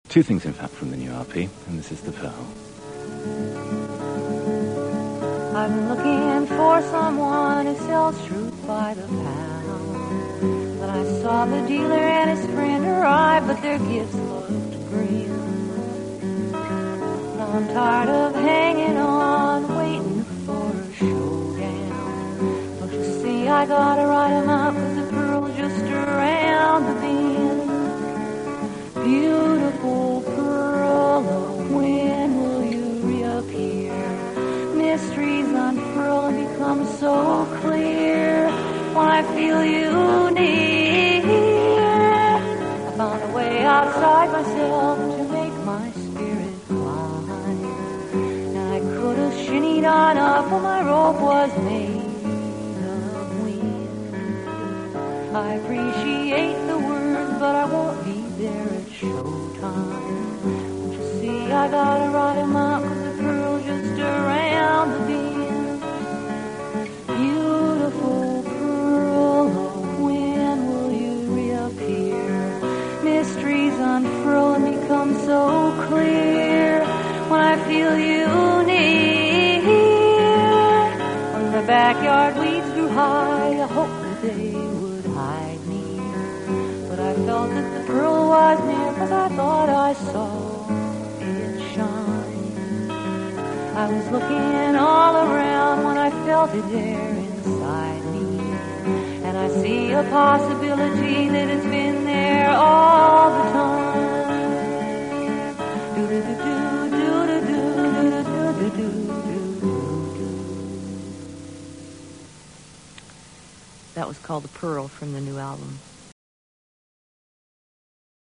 (again, TV recorded with a microphone)